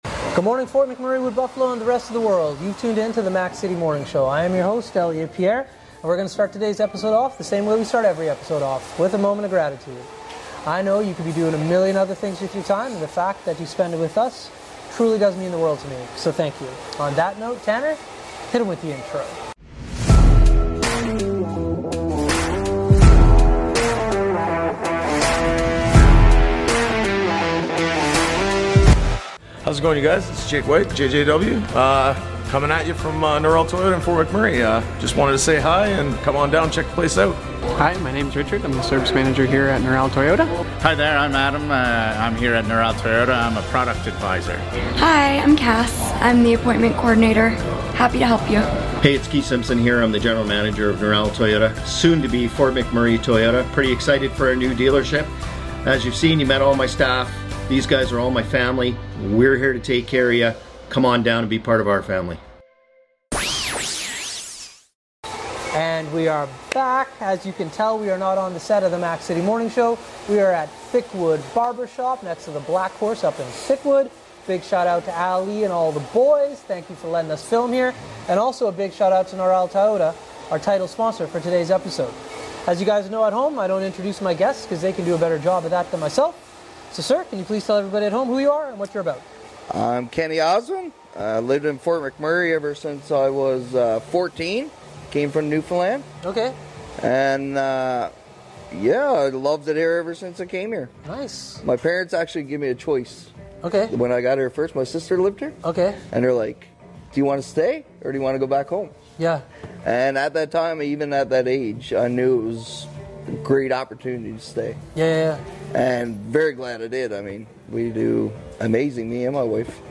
We are on locations today at Thickwood Barbershop!